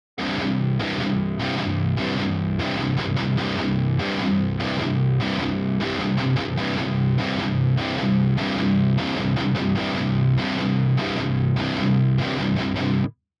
Ну, я исключительно с 11-56 сравниваю на тех же настройках, в целом, они так и должны звучать, потому что более толстые струны всегда звучат темнее и чем больше натяжение, тем меньше обертонов. Там, короче, даже саб низ присутствует, судя по анализатору.